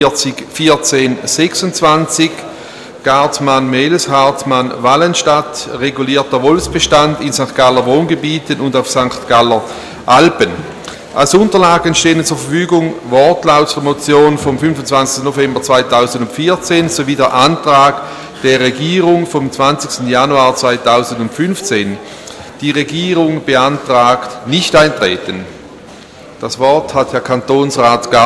Ratsvizepräsident: Die Regierung beantragt Nichteintreten auf die Motion.
Session des Kantonsrates vom 23. bis 25. Februar 2015